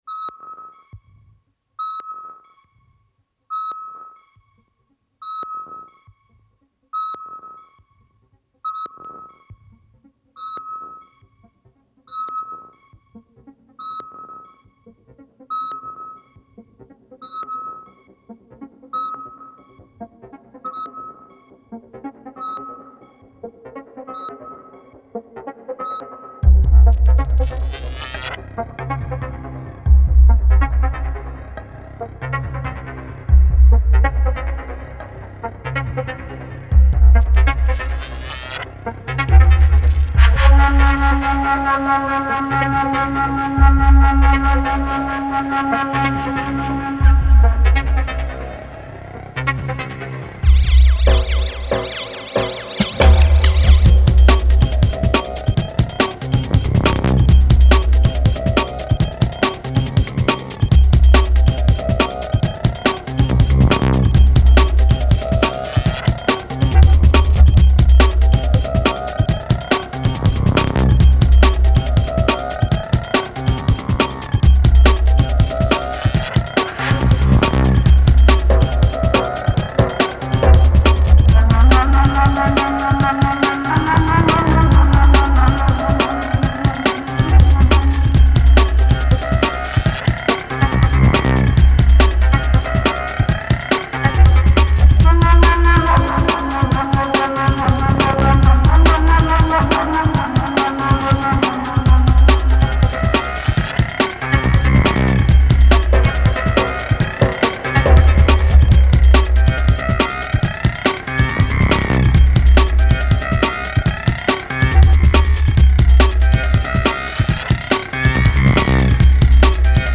Style: Breakbeat / Bass Music / Experimental